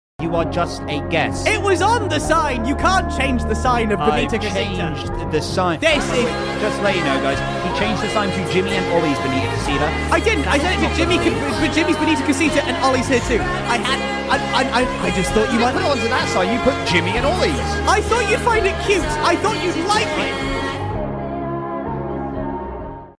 sorry for bad quality, its a combination of my phone didn’t put the clips to 1080p, then I had to screen record the edit from capcut so I didn’t have to pay and idk if this app also makes the quality worse